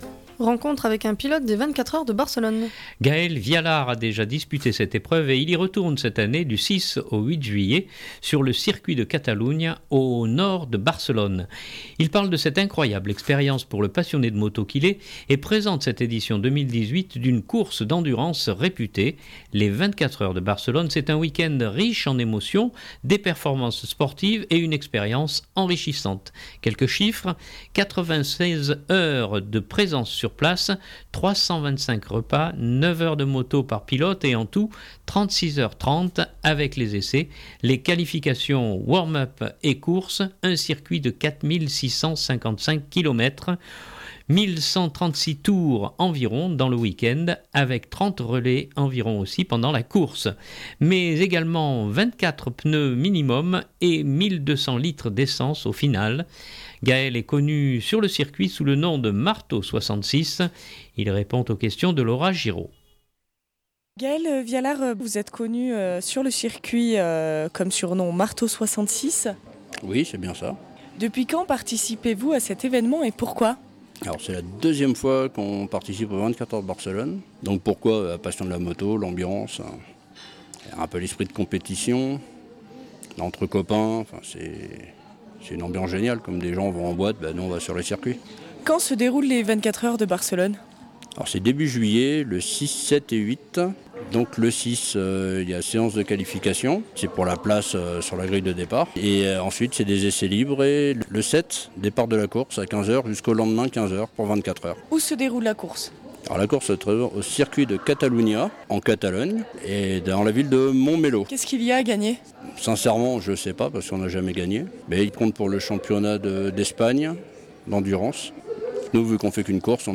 Rencontre avec un pilote des 24 heures de Barcelone